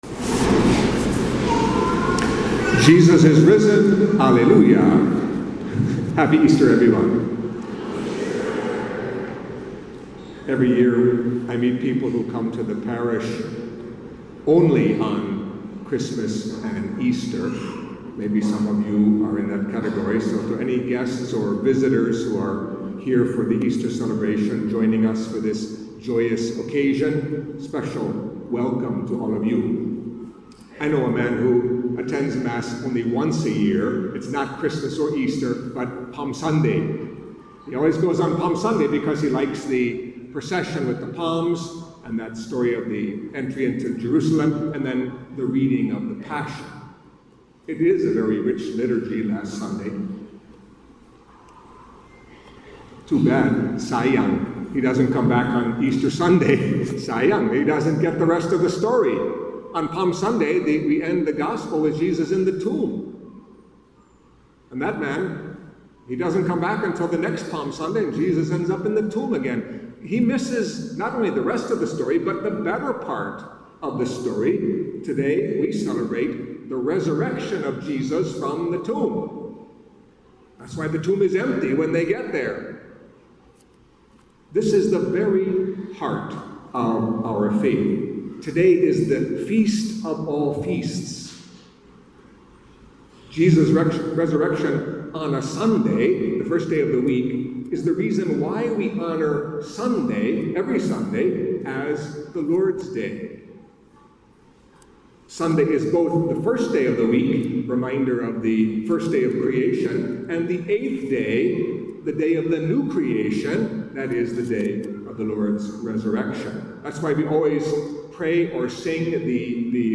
Catholic Mass homily for Easter Sunday of the Resurrection of the Lord